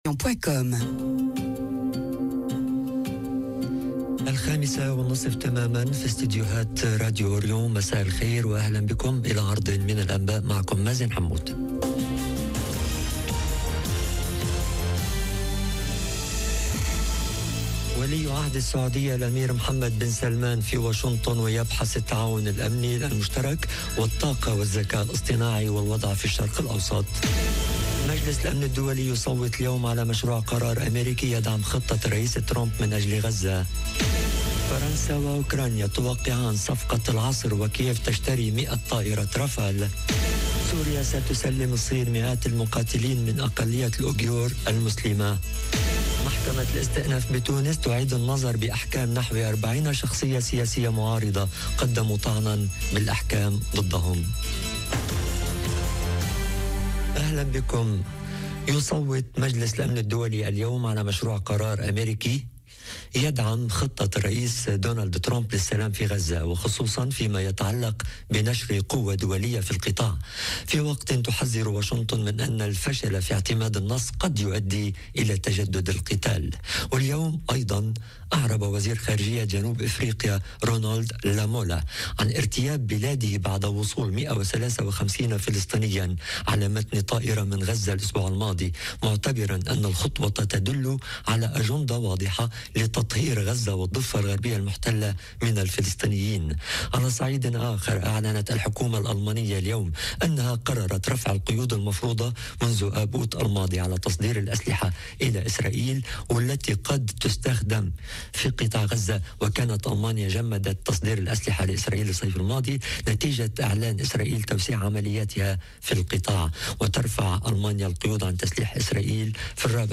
نشرة أخبار المساء: - ولي عهد السعودية الأمير محمد بن سلمان في واشنطن ويبحث التعاون الأمني المشترك والطاقة والذكاء الاصطناعي والوضع في الشرق الاوسط - Radio ORIENT، إذاعة الشرق من باريس